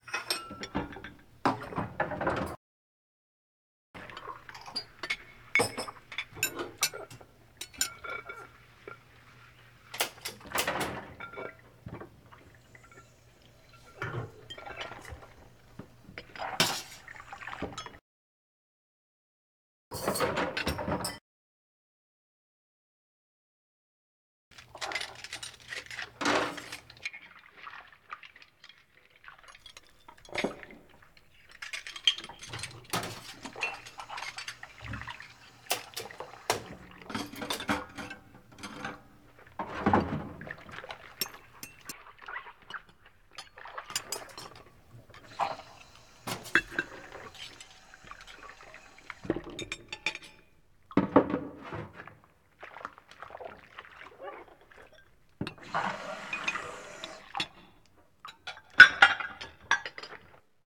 SND_cafe_ambient_loop.ogg